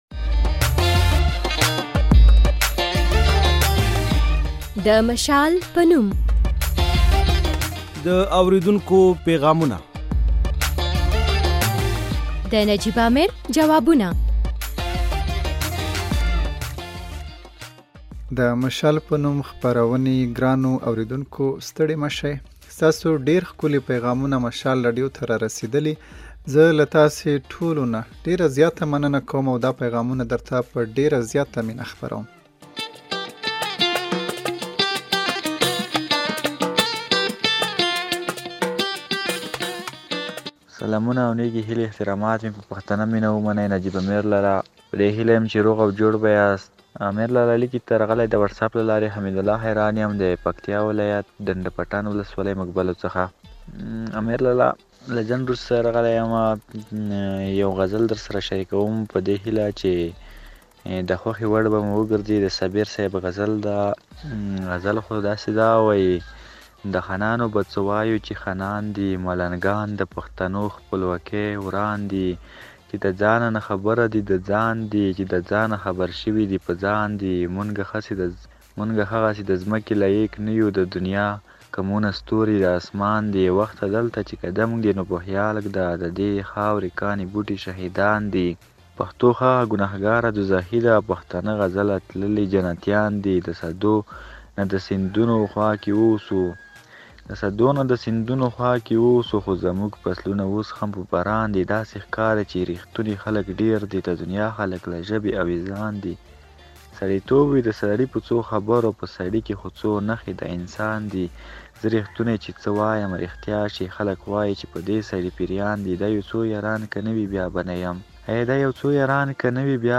د مشال په نوم خپرونه د اوریدونکیو له پیغامونو او د مشال د همکار له ځوابونو جوړه ده. په دې خپرونه کې اوریدونکي په روانو چارو تبصرې کوي، شعرونه لولي، زمزمې کوي او خپلې خاطرې راسره شریکوي.